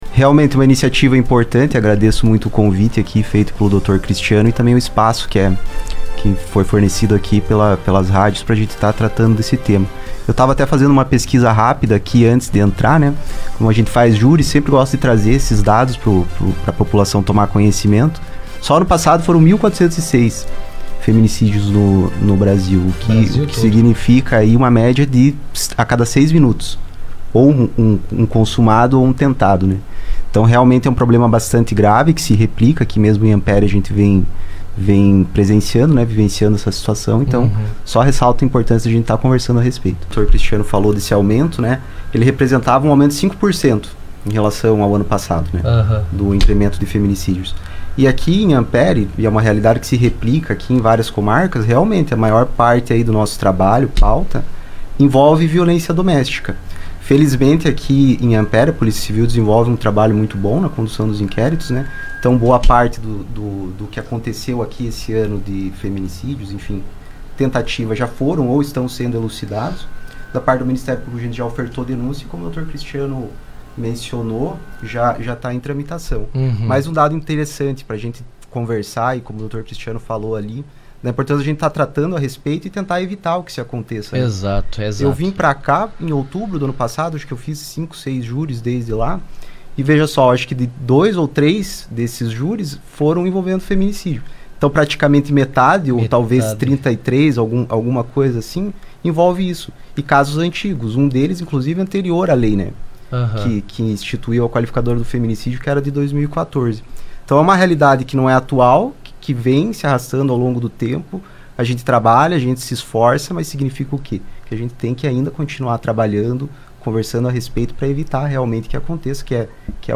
O Promotor Público, Igor Corso, ressalta que essa uma iniciativa muito importante.